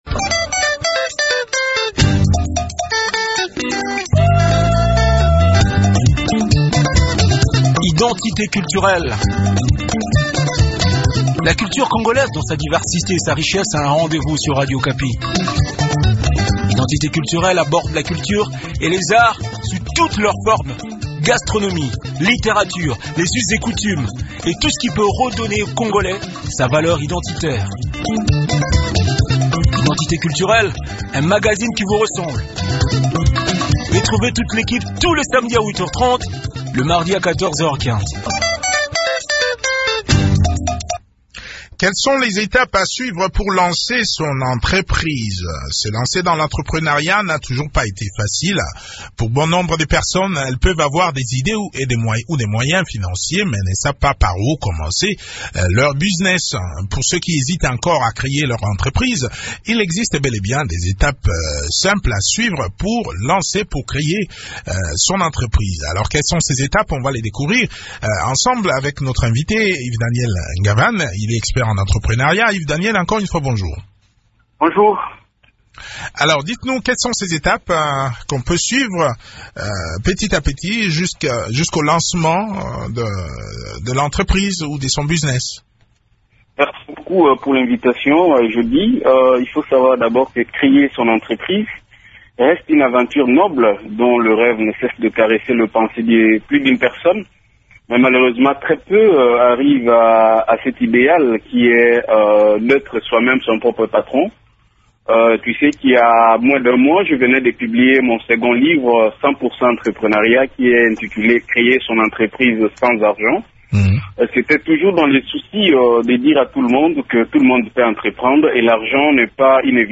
expert en entreprenariat, répond aux questions des auditeurs